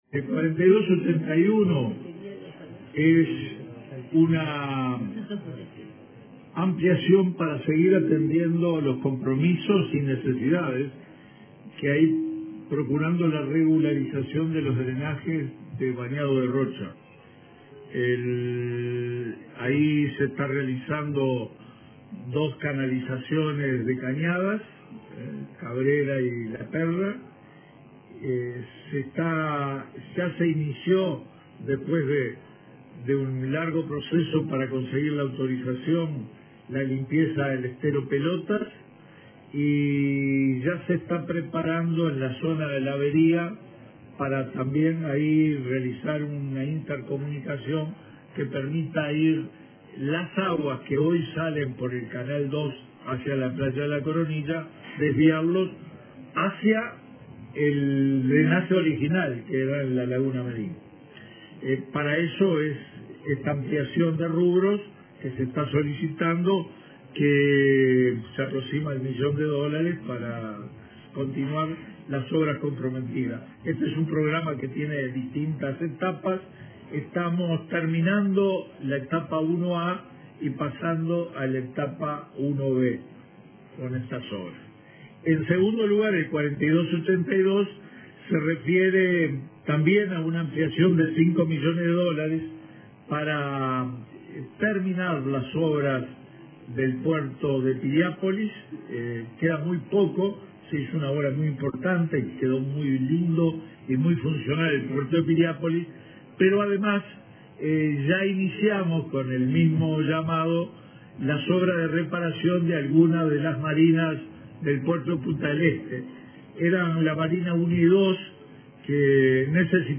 Antes del comienzo de la temporada de verano, se culminarán las obras de infraestructura en el puerto de Piriápolis y la reparación de las marinas uno y dos del de Punta del Este, señaló el ministro de Transporte y Obras Públicas, Víctor Rossi, en el Consejo de Ministros abierto de este lunes 7 en La Macana, Florida. El ministerio invertirá cinco millones de dólares. También habló de las obras en ruta 5.